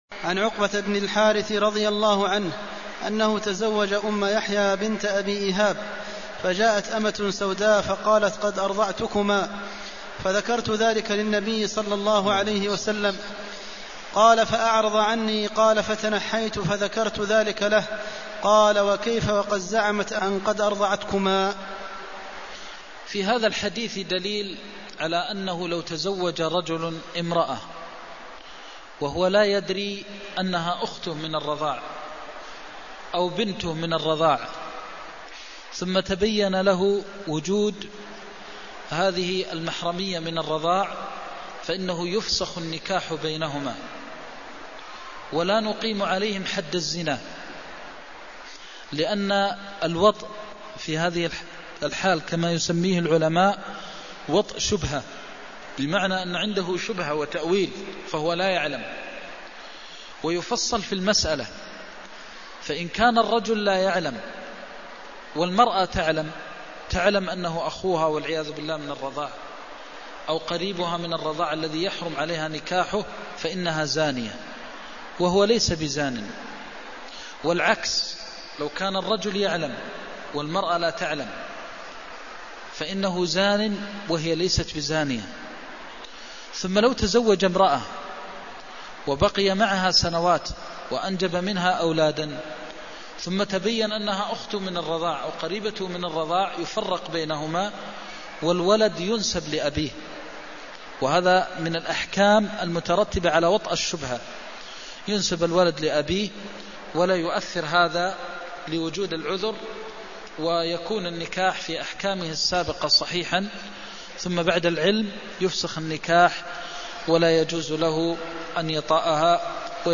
المكان: المسجد النبوي الشيخ: فضيلة الشيخ د. محمد بن محمد المختار فضيلة الشيخ د. محمد بن محمد المختار وكيف وقد زعمت أن قد أرضعتكما (317)